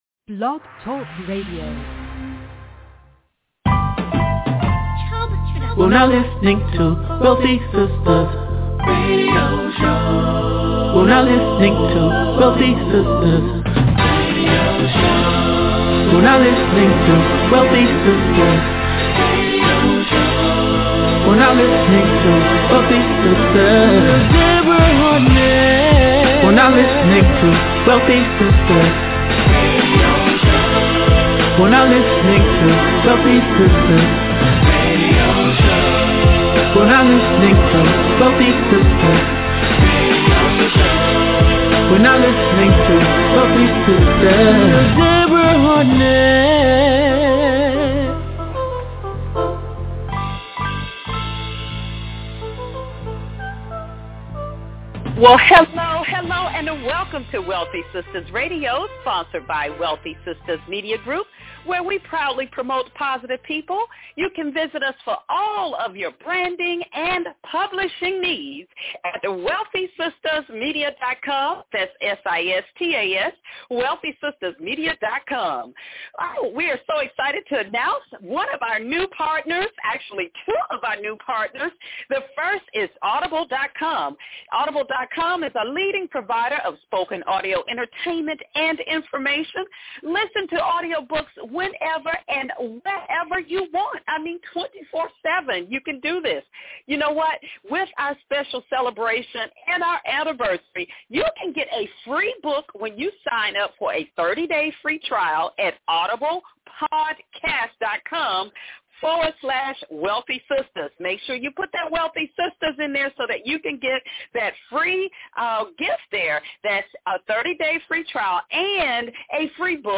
And is intertwined with some of the greatest R&B music of all time.